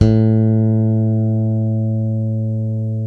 Index of /90_sSampleCDs/Roland L-CD701/BS _Jazz Bass/BS _Jazz Basses